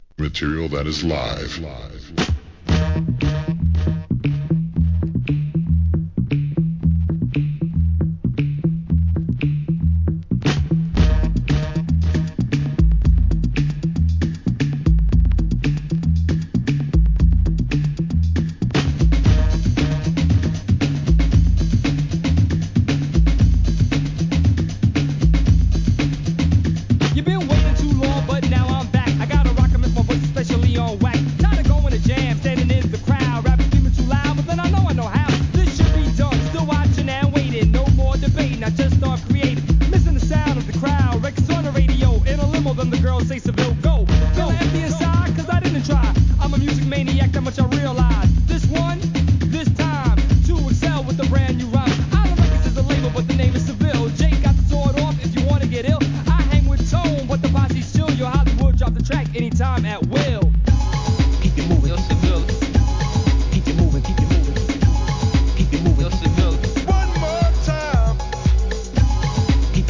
HIP HOP/R&B
メガミックス物好きにもオススメできる1989年アッパー・ミドル!!